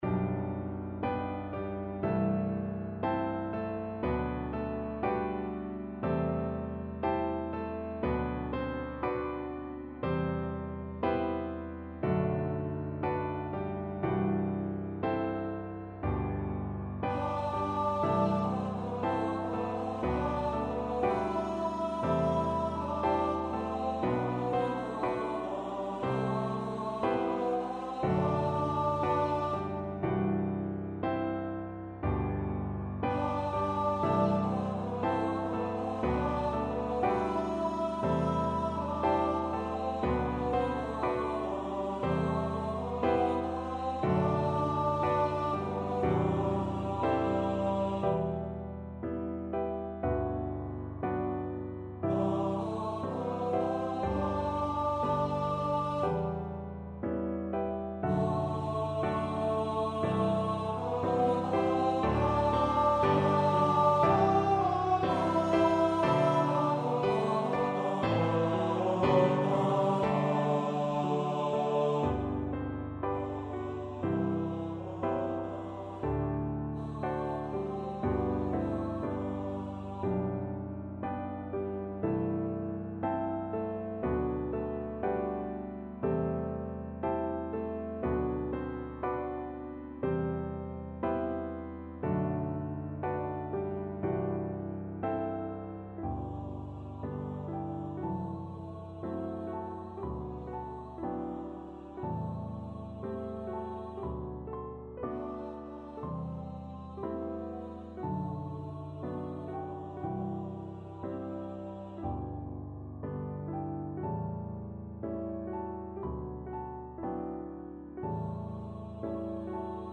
Moderato con moto =60
4/4 (View more 4/4 Music)
Classical (View more Classical Tenor Voice Music)